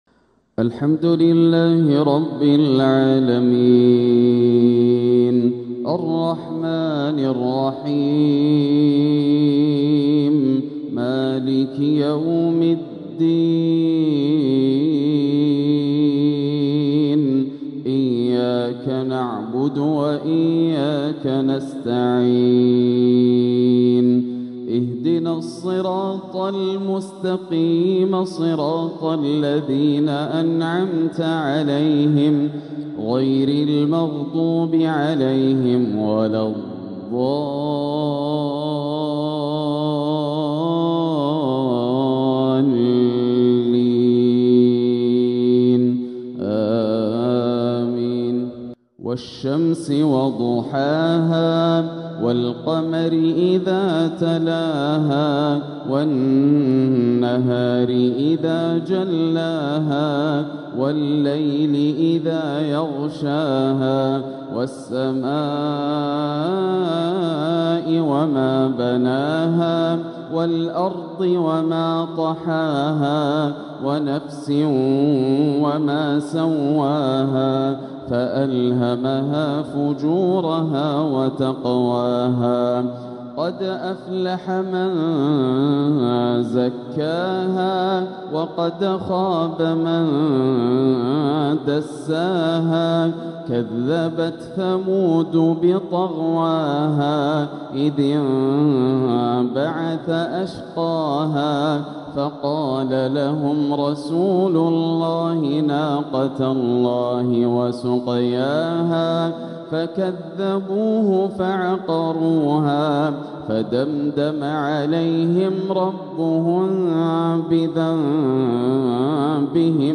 العقد الآسر لتلاوات الشيخ ياسر الدوسري تلاوات شهر ربيع الأول عام 1447هـ من الحرم المكي > سلسلة العقد الآسر من تلاوات الشيخ ياسر > المزيد - تلاوات ياسر الدوسري